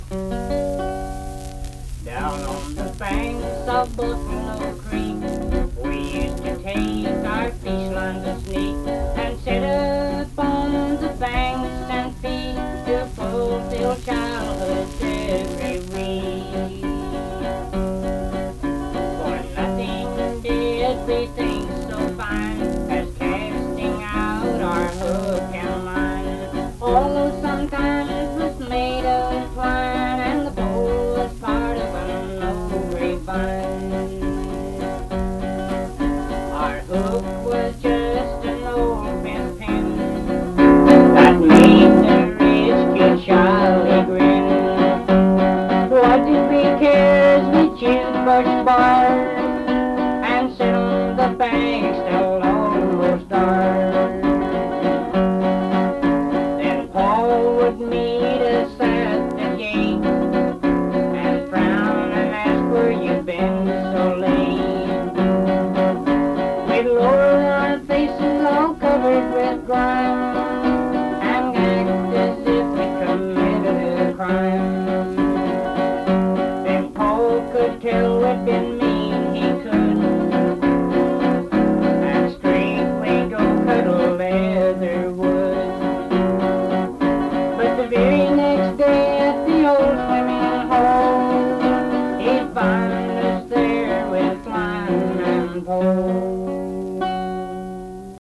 Unaccompanied fiddle music and accompanied (guitar) vocal music
Miscellaneous--Musical
Guitar, Voice (sung)